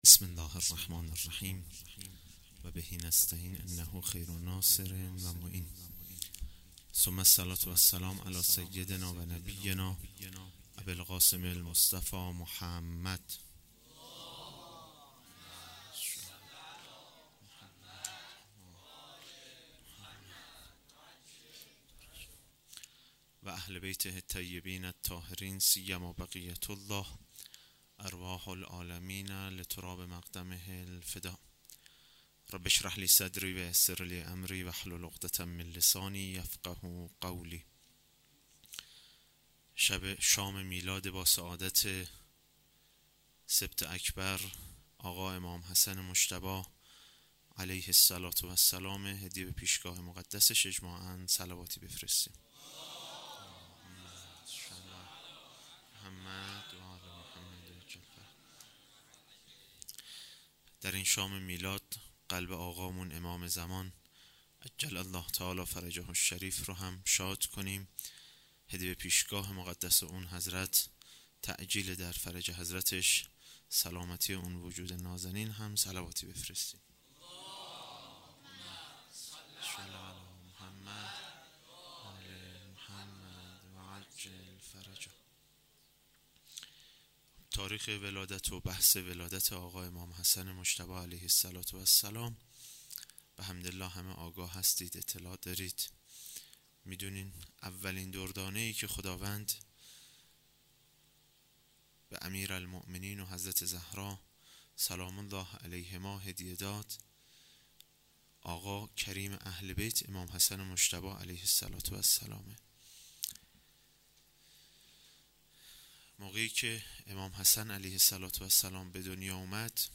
میاد امام حسن (ع) - هیات لثارات الحسین(ع)- حوزه علمیه نخبگان
sokhanrani.mp3